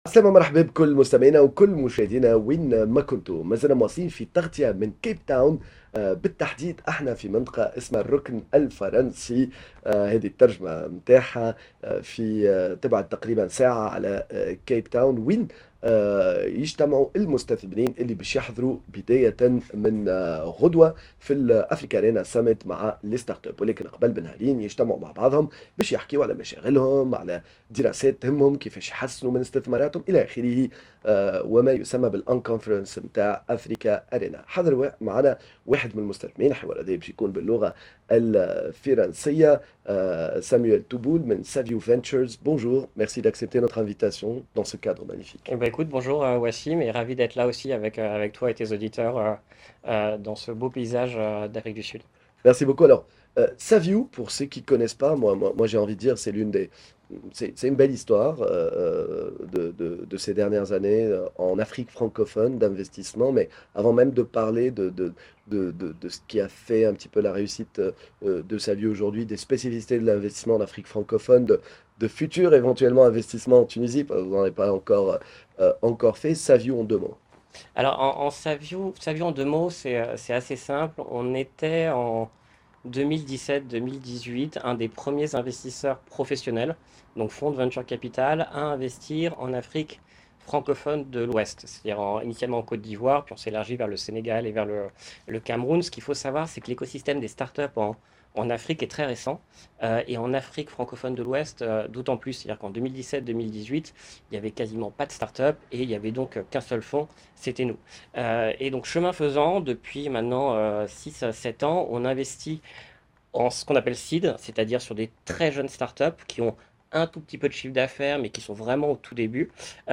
en direct de l'un des plus importants événements d'investisseurs en startups Africarena à Capetown